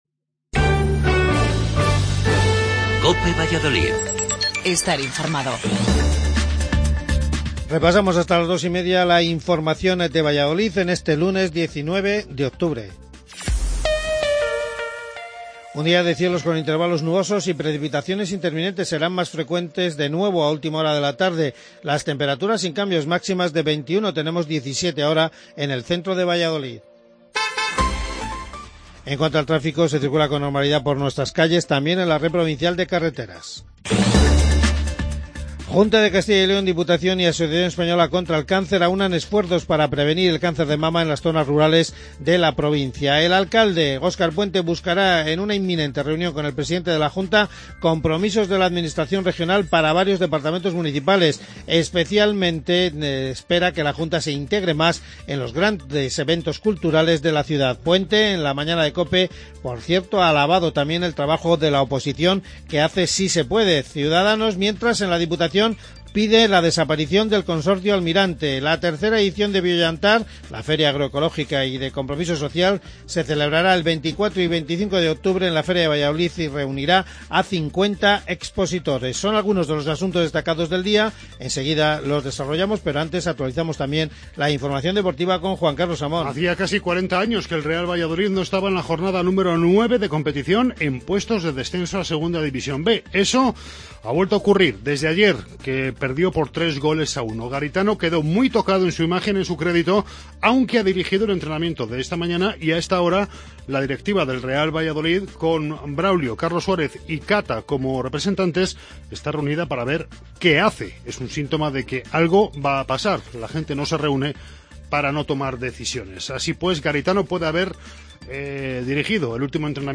AUDIO: Informativo local